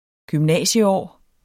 Udtale [ gymˈnæˀɕəˌɒˀ ]